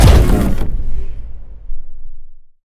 droidic sounds
hurt1.ogg